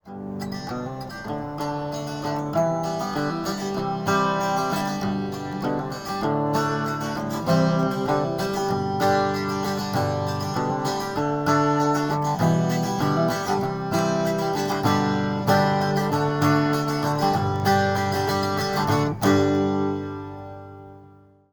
Just because I'm having a strum as I read this...